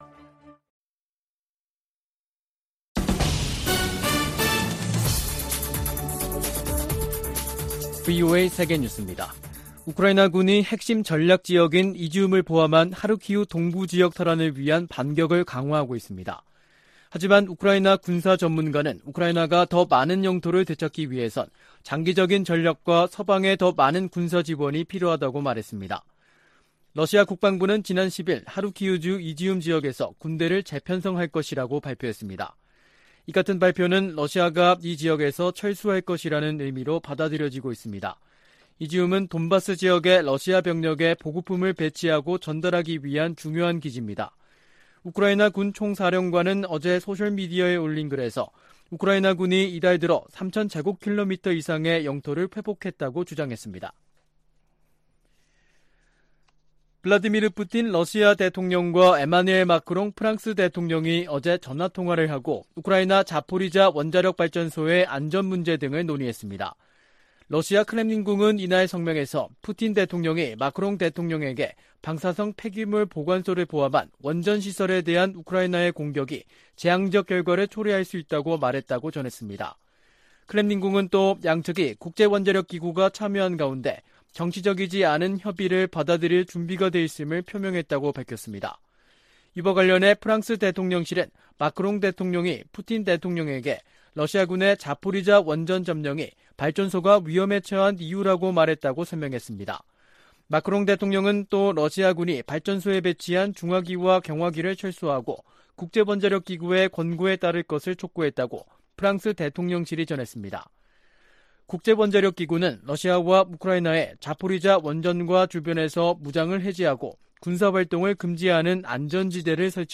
VOA 한국어 간판 뉴스 프로그램 '뉴스 투데이', 2022년 9월 12일 3부 방송입니다. 북한이 경제난 속 ‘핵 법제화’를 강행하는 것은 중러와의 3각 밀착이 뒷받침을 하고 있다는 분석이 나오고 있습니다.